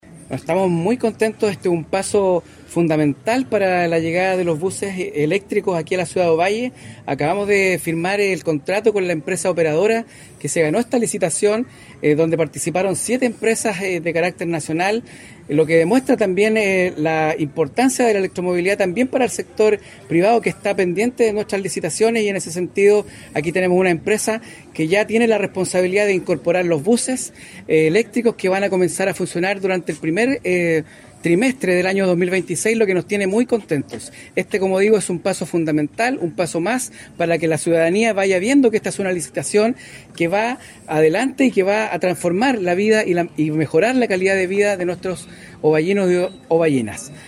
ELECTROMOVILIDAD-OVALLE-Jorge-Daza-Ministro-s-de-Transportes.mp3